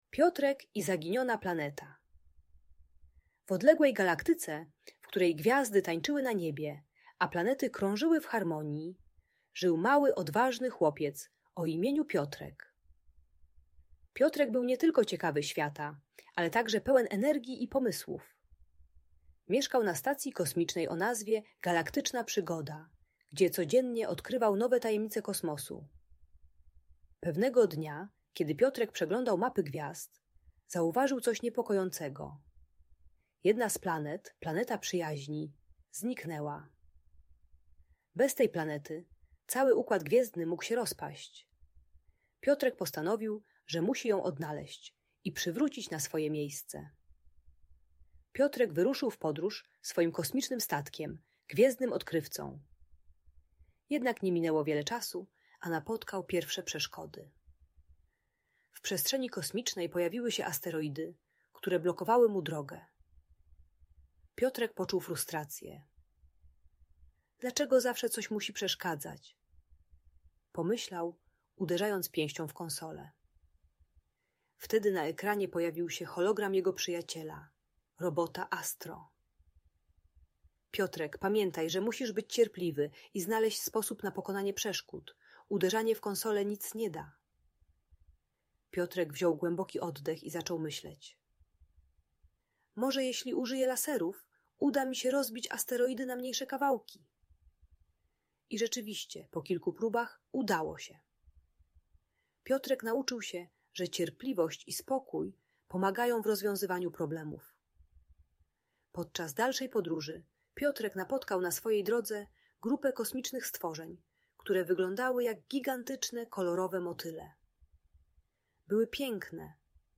Piotrek i Zaginiona Planeta - Agresja do rodziców | Audiobajka